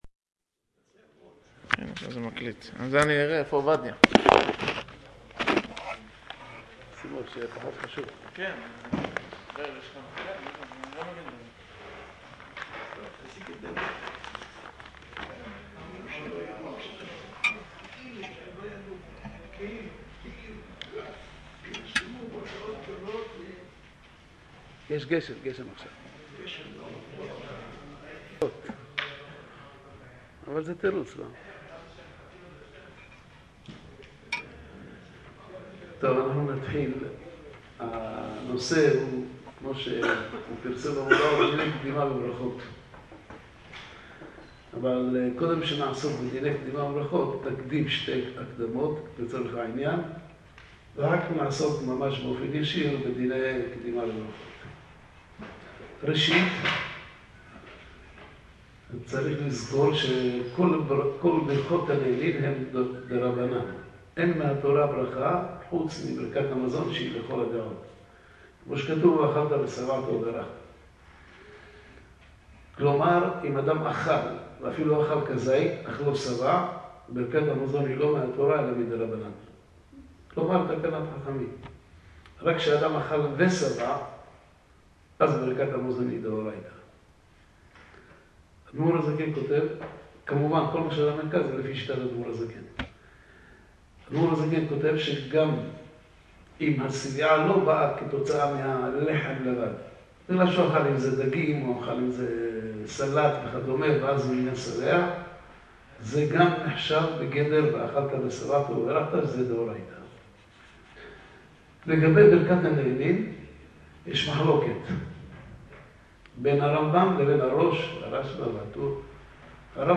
שיעור לרגל ט"ו בשבט בדיני קדימה בברכות ● להאזנה